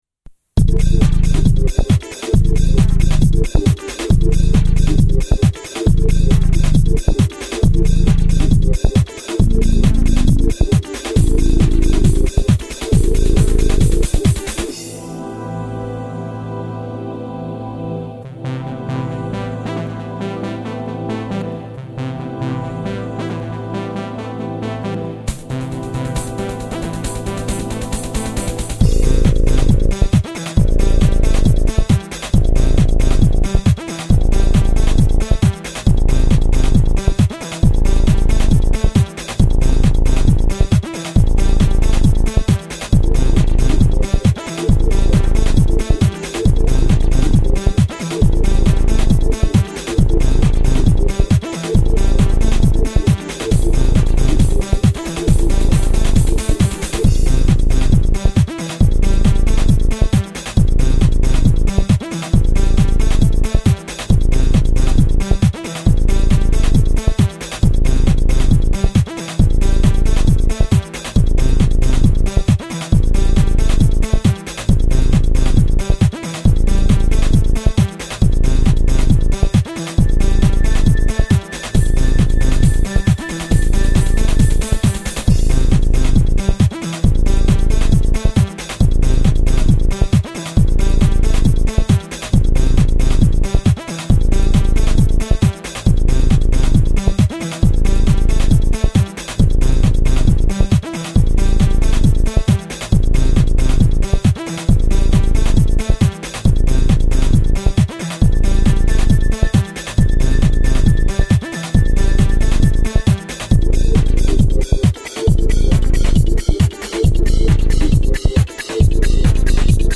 Фоновая музыка для роликов про космос (не для Youtube)